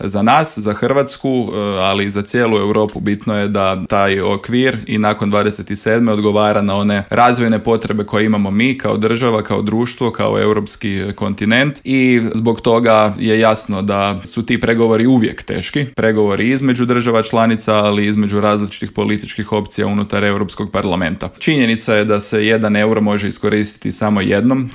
ZAGREB - "Europa je godinama zanemarivala pitanje obrane i sigurnosti i to se sada mijenja iz temelja jer naš odgovor ne može biti samo deklaratoran nego i konkretan", u Intervjuu Media servisa poručio je eurozastupnik iz redova HDZ-a Karlo Ressler.